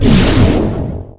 RIPPLE5.mp3